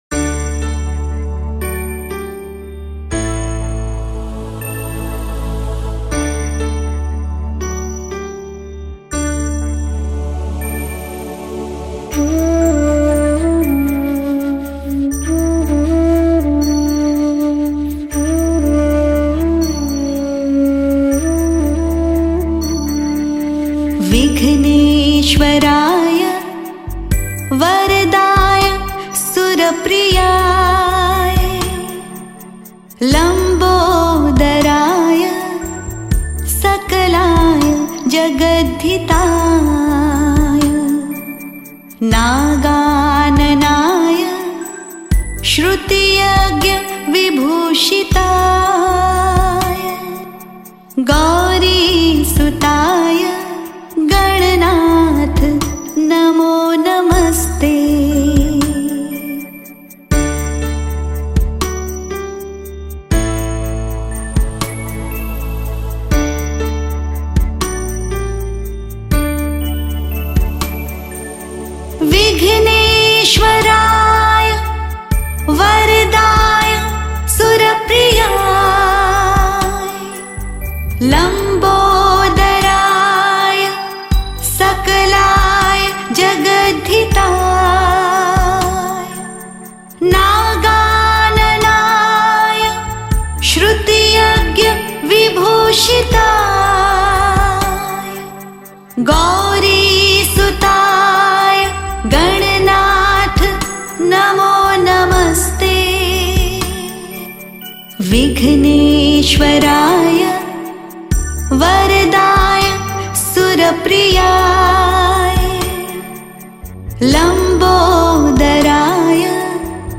Bhakti
Ganesh Bhajan